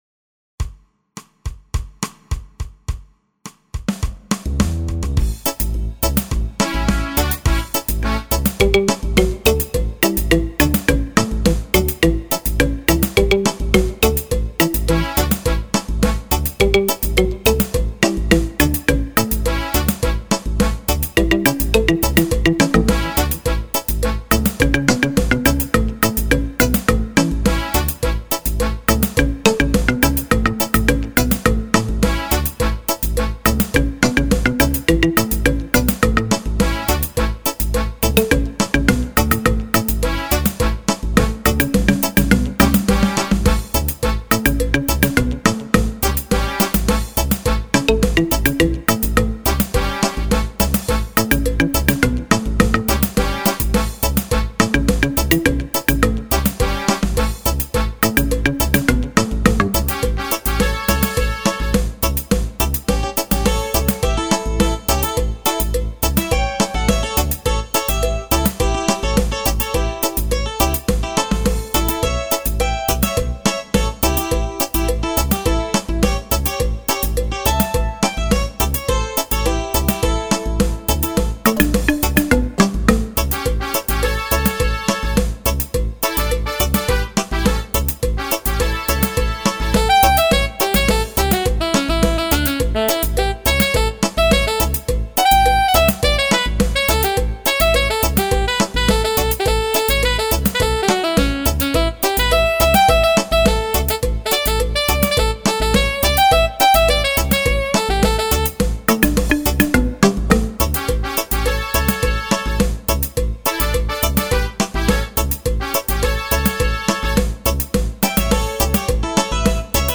Afro-Latin dance rhythm.